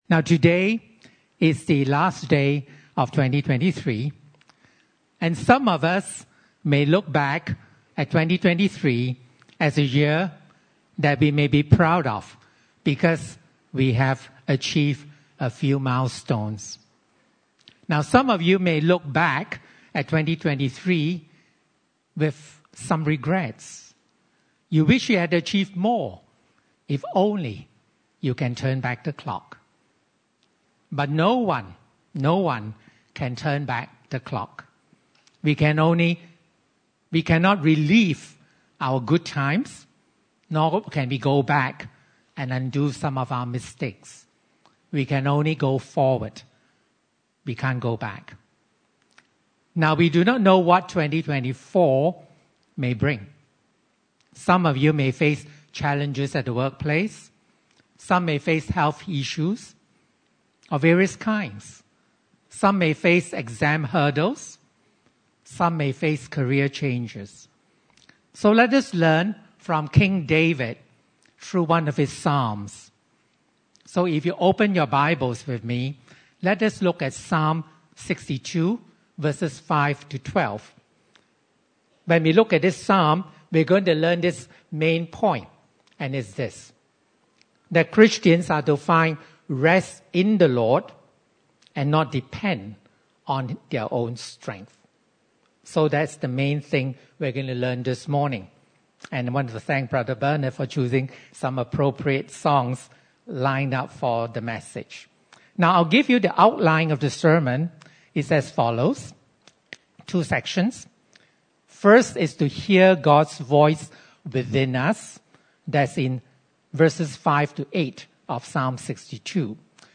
Psalm 62:5-12 Service Type: Sunday Service